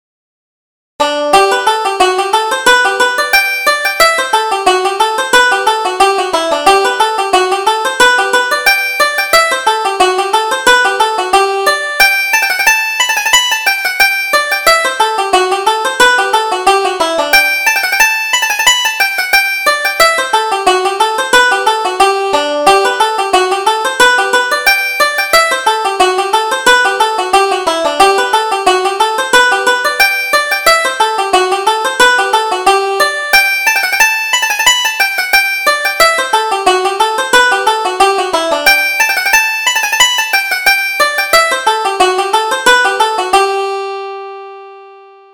Reel: Kiss the Bride